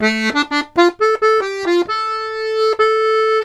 Index of /90_sSampleCDs/USB Soundscan vol.40 - Complete Accordions [AKAI] 1CD/Partition C/03-130DIGRIN